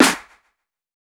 TC2 Snare 9.wav